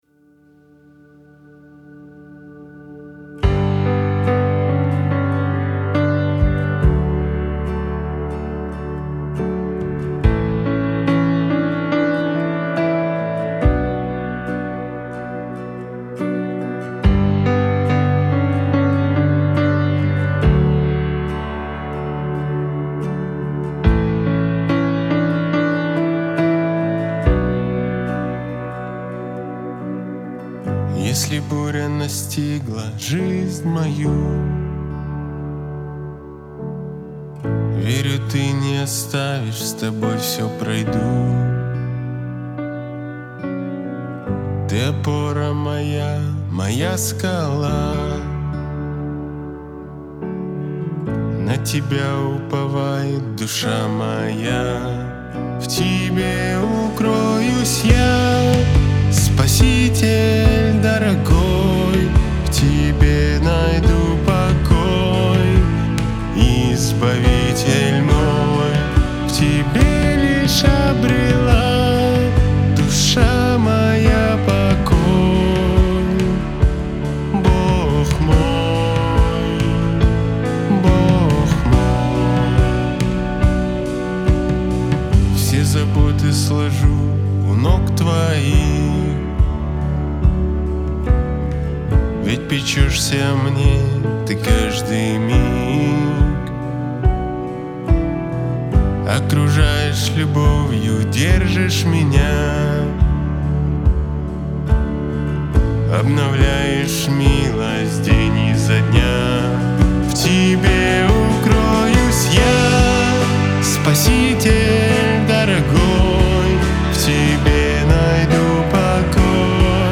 833 просмотра 683 прослушивания 70 скачиваний BPM: 141